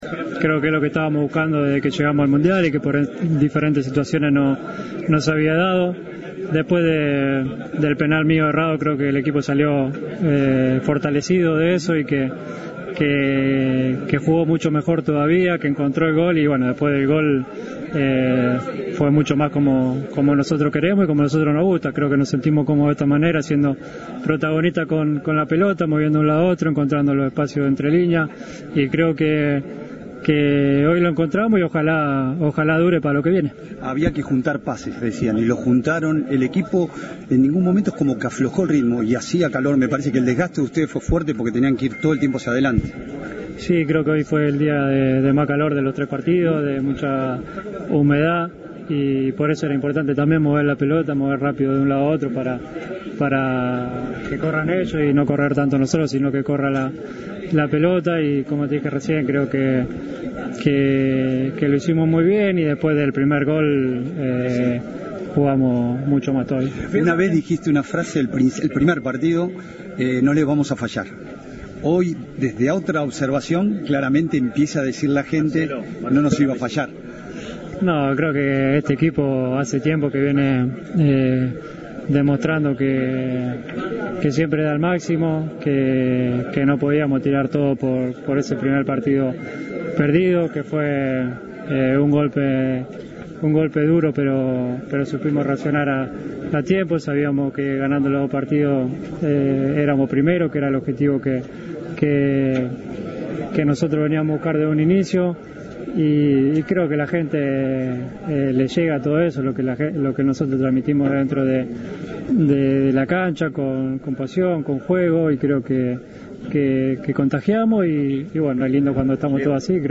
El 10 argentino habló luego de la clasificación a octavos de final.
"Ahora es momento en que debemos estar tranquilos e ir partido a partido. Empieza otro Mundial y ojalá podamos seguir manteniendo lo que hicimos hoy", estimó Messi en declaraciones a los medios, entre los que estaba Cadena 3, al término del encuentro por el Mundial de Qatar.